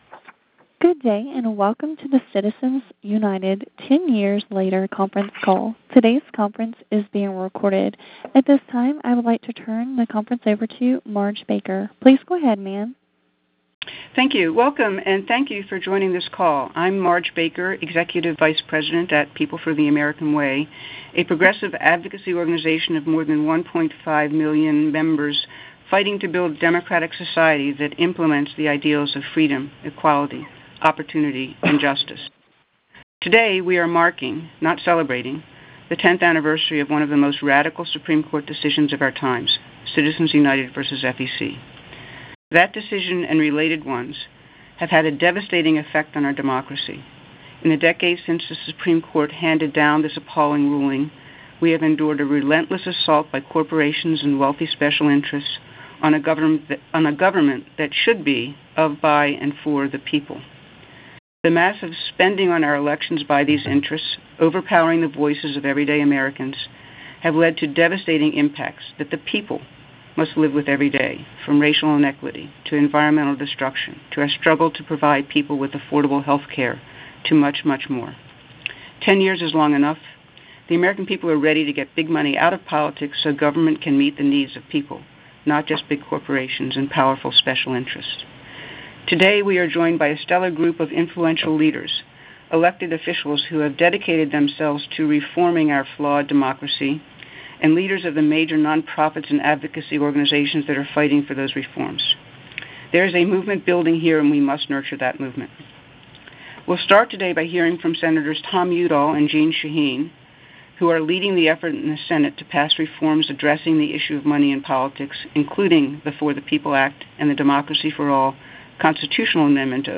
Download File: Citizens United Press Call | U.S. Senator Jeanne Shaheen of New Hampshire
citizens-united-press-call